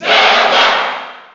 Zelda_Cheer_NTSC_SSB4.ogg